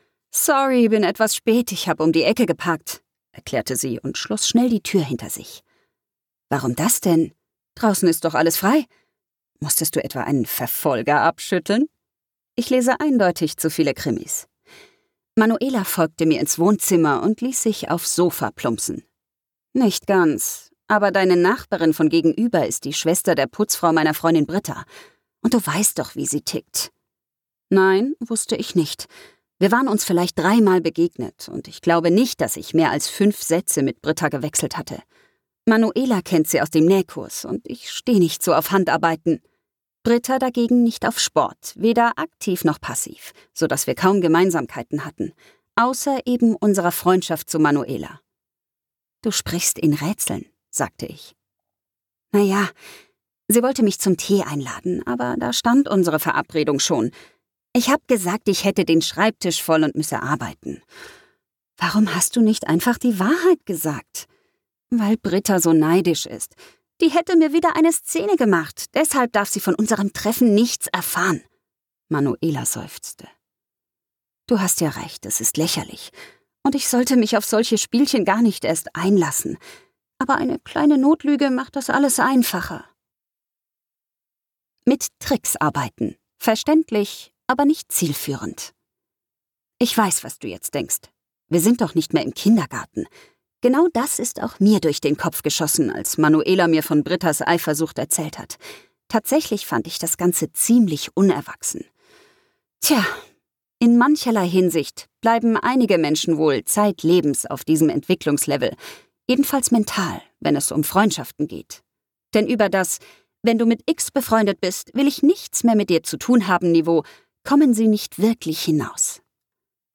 Format: Download-Hörbuch  (MP3)
Fassung: Ungekürzte Ausgabe